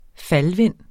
Udtale [ ˈfal- ]